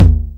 44_02_tom.wav